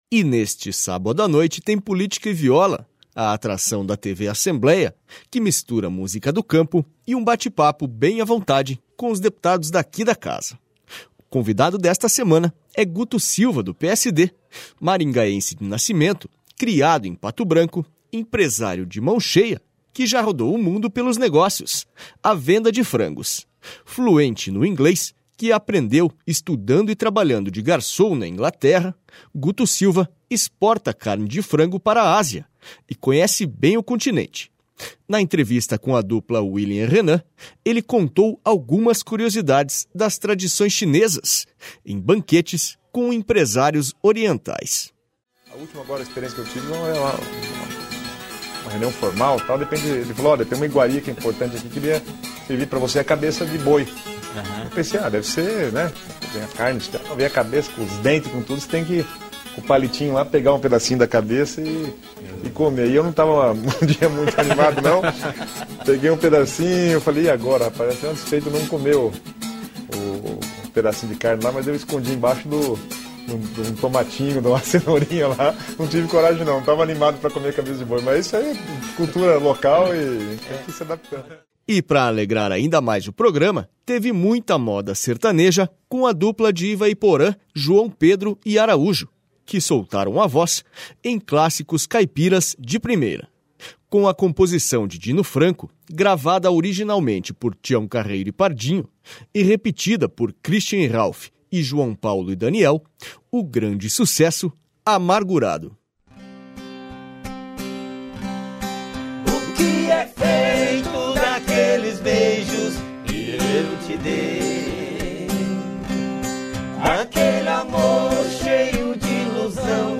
E neste sábado à noite tem Política e Viola, a atração da TV Assembleia que mistura música do campo e um bate papo bem à vontade com os deputados daqui da casa.// O convidado desta semana é Guto Silva, do PSD, maringaense de nascimento, criado em Pato Branco, empresário de mão cheia que já rodou o m...